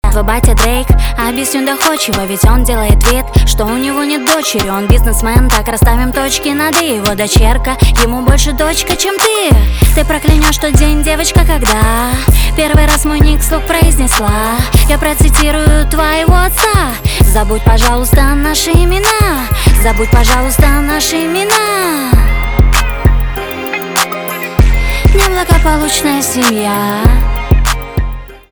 поп
жесткие , грустные , печальные , битовые , басы , гитара